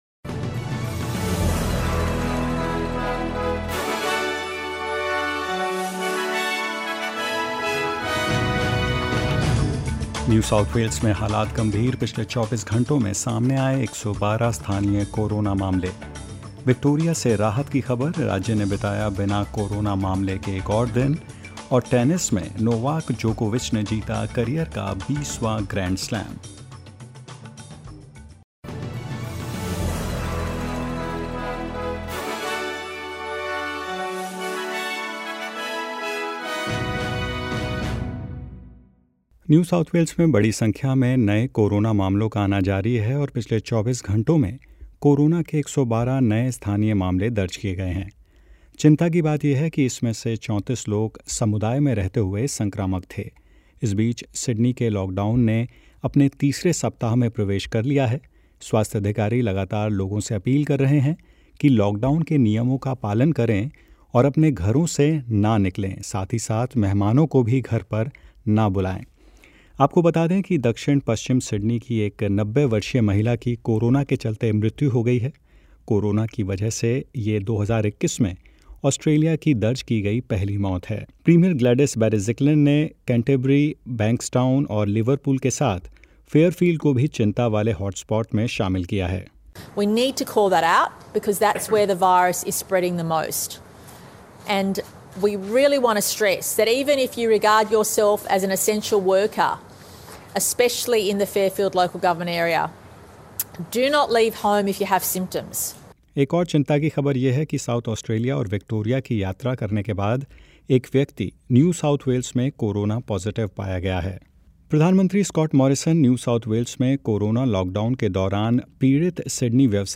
In this latest SBS Hindi News bulletin of Australia and India: Victoria and South Australia on alert after traveler found Covid positive in NSW; Novak Djokovic wins his 20th Grand Slam and more.